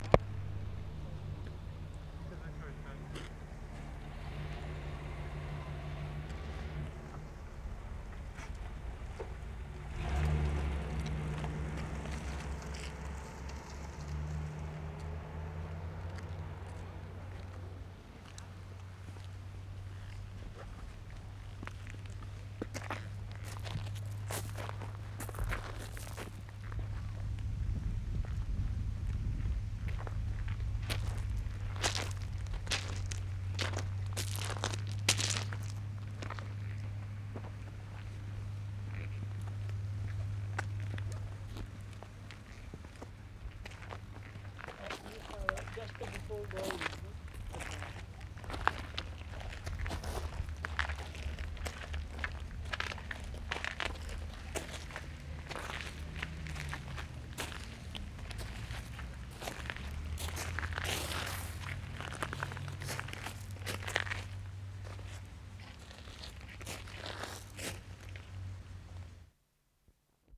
11. Entering the gardens from the parking lot.